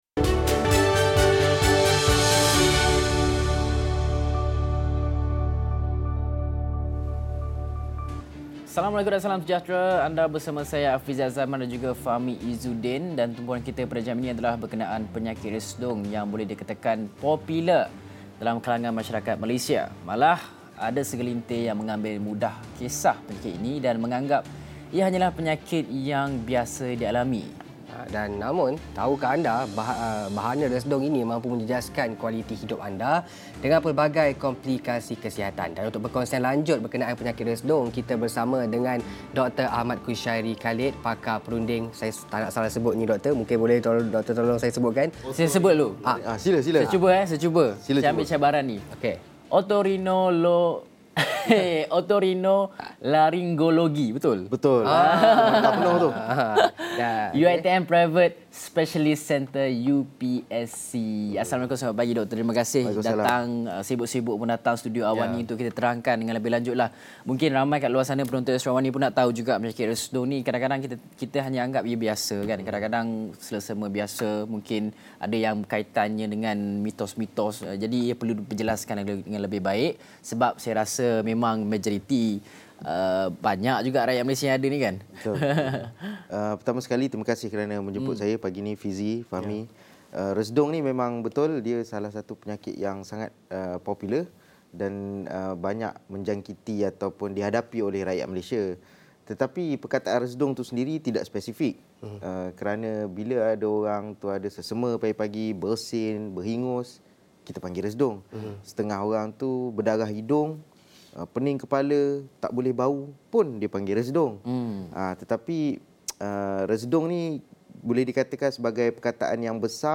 Ikuti diskusi mengenai resdung, simptom dan kaedah rawatannya